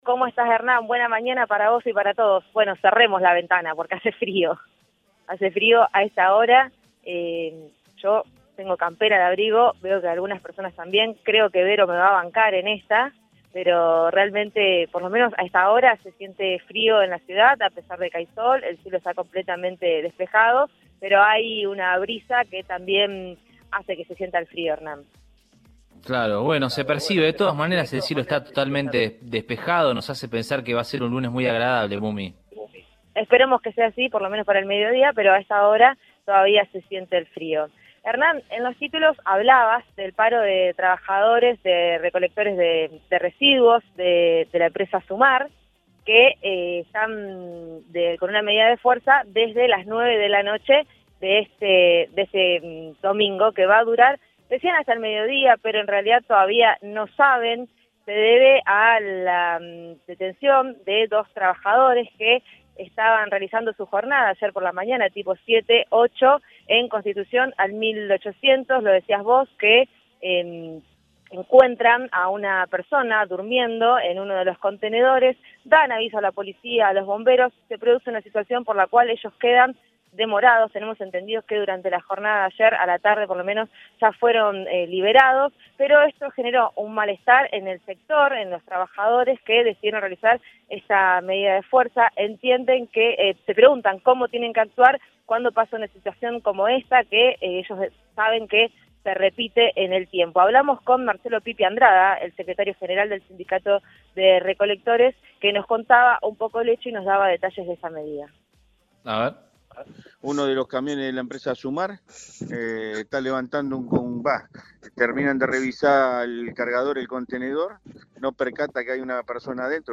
confirmó al móvil de Cadena 3 Rosario